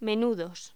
Locución: Menudos